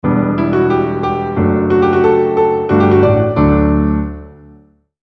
äänilogo Piano